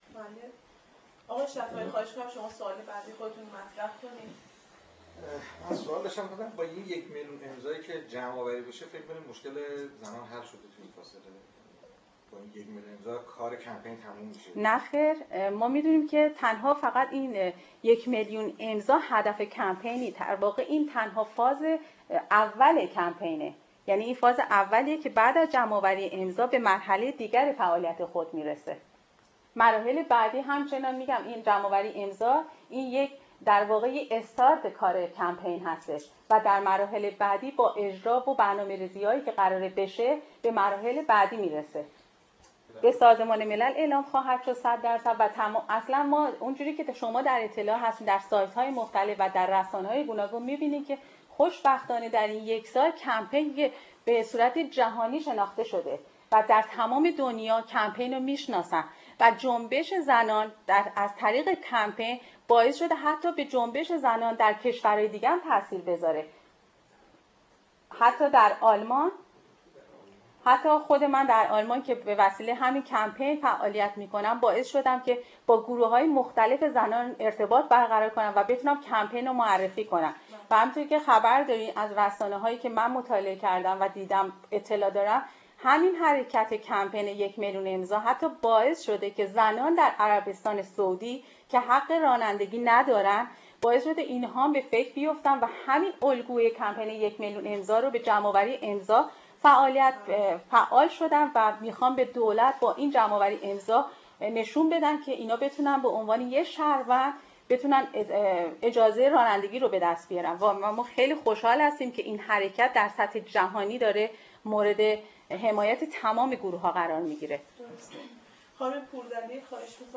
پرسش و پاسخ 1